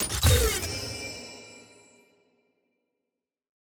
sfx-perks-prec-keystone-ff.ogg